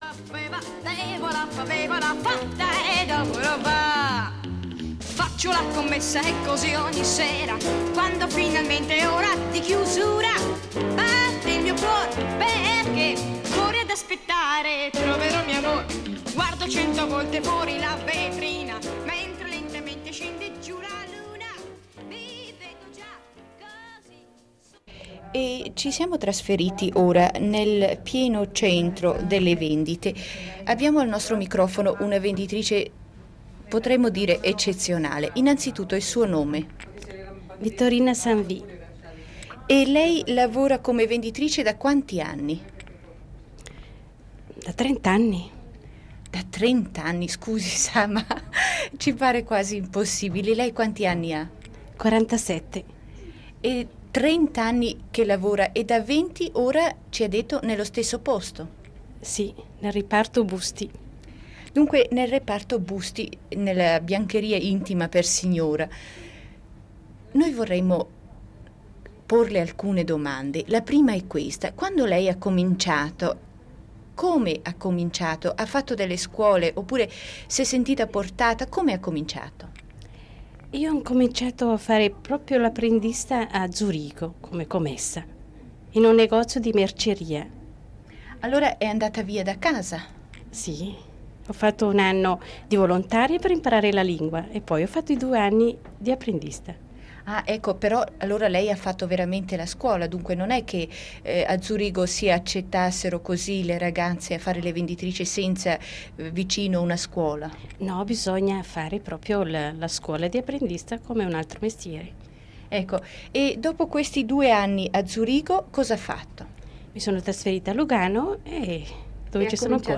20.08.18 "Le professioni femminili". Intervista